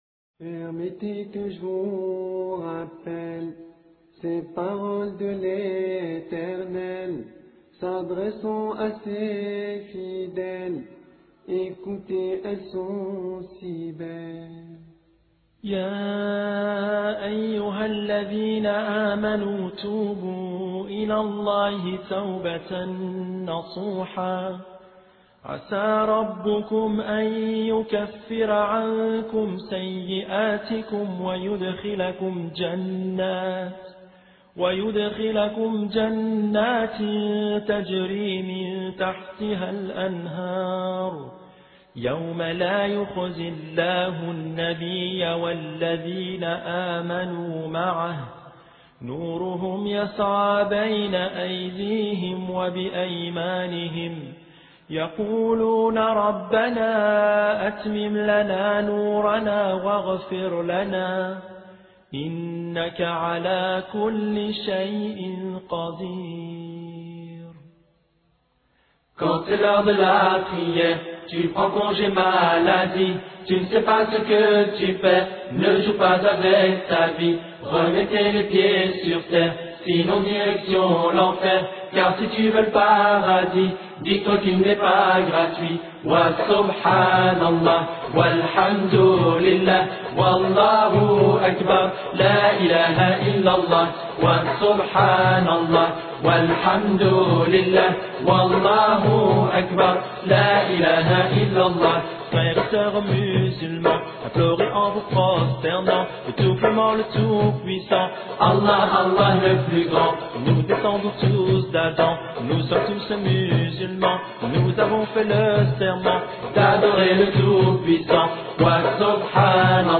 guidance orateur: quelques jeunes période de temps: 00:00:00